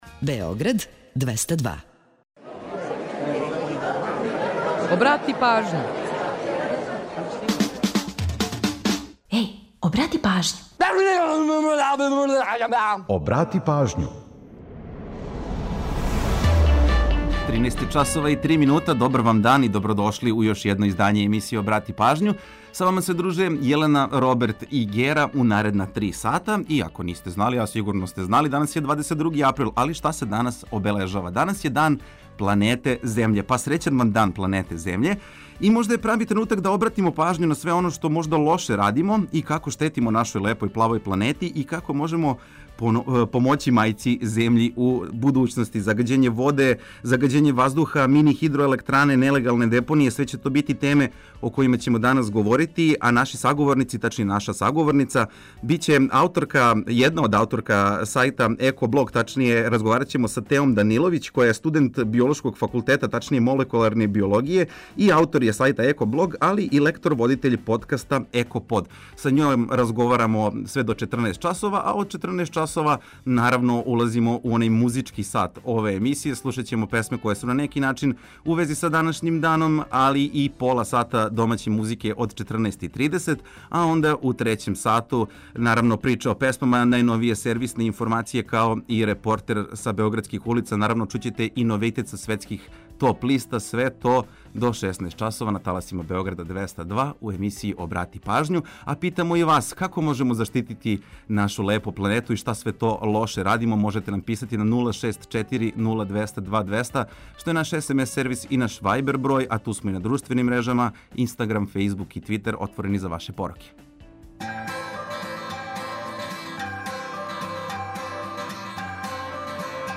Са ауторима сајта ЕкоБлог разговараћемо о томе шта све радимо лоше, као и шта све то боље у будућности можемо да радимо. Загађење, отпад, мини-хидроелектране, рециклажа - све су то теме данашње емисије.
Ту је и пола сата резервисаних за домаћицу, музику из Србије и региона, прича о једној песми и низ актуелних занимљивости и важних информација.